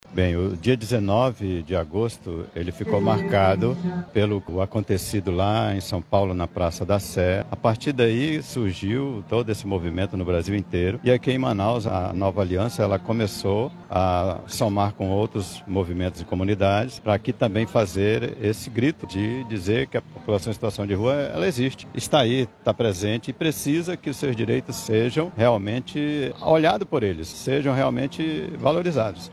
Em referência ao Dia de Luta da População em Situação de Rua, a Rede Pop Rua, realizou nesta terça-feira, 19 de agosto, uma mobilização na Praça da Matriz, no Centro de Manaus.